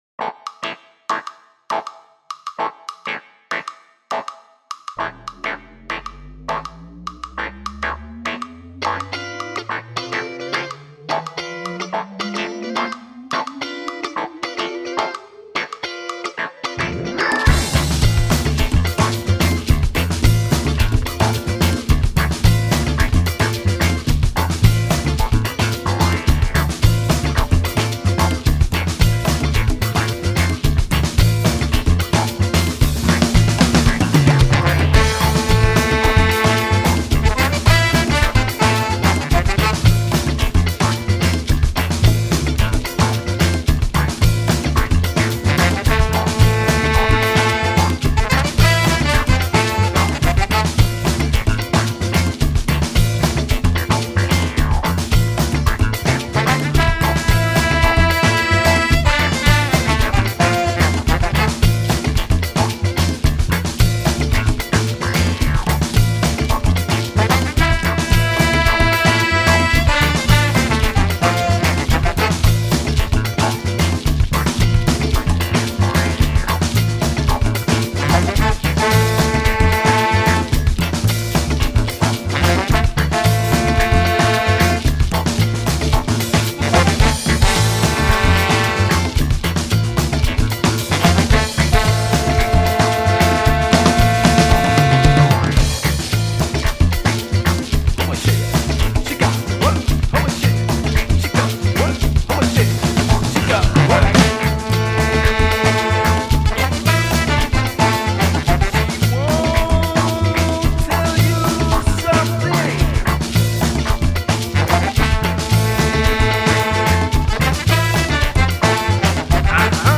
Tight horn riffs, dansable grooves, catchy songs.